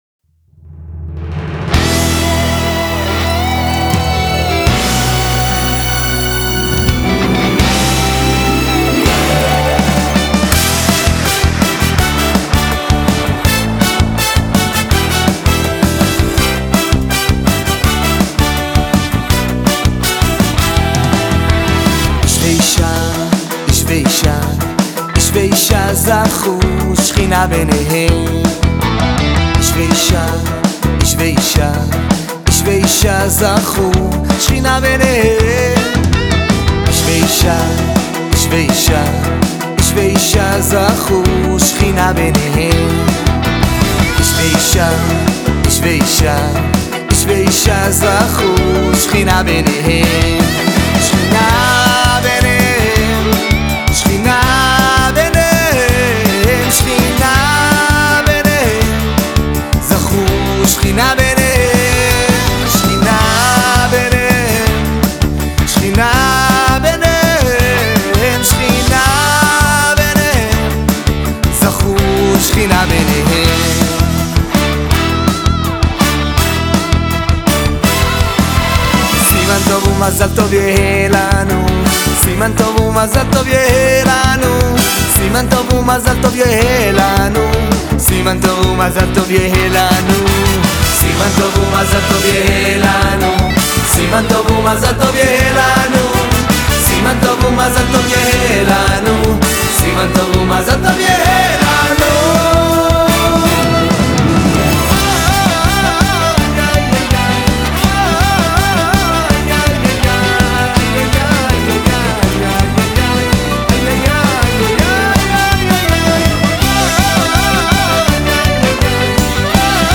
להיט חתונות חדש על מילותיו של רבי עקיבא בגמרא במסכת סוטה